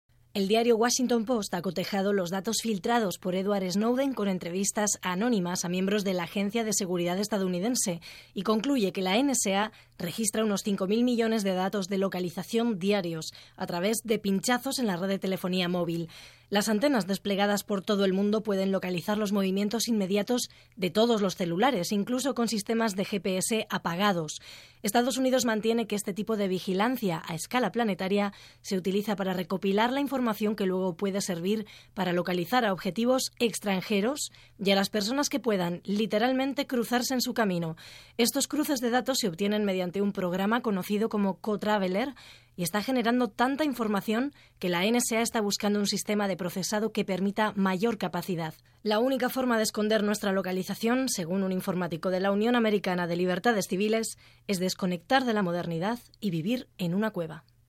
Informació des de Washington.
Informatiu